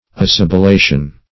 Assibilation \As*sib`i*la"tion\, n.